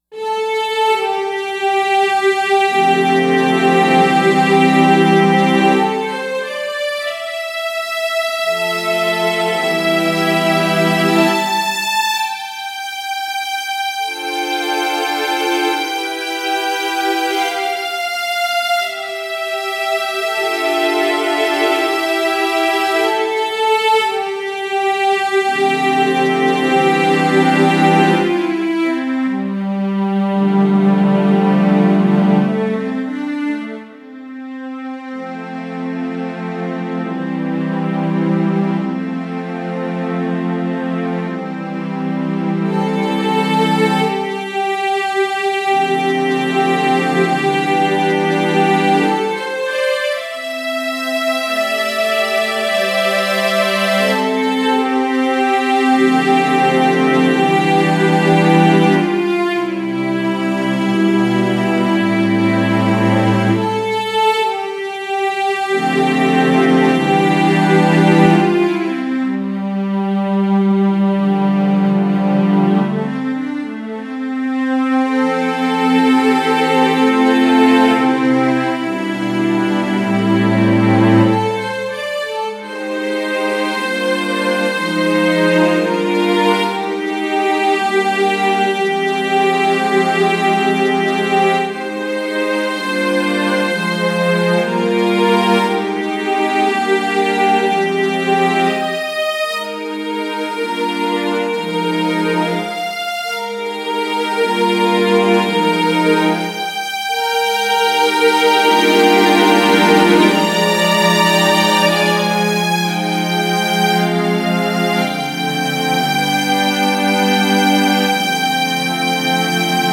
solo on Roland brand synthesizer.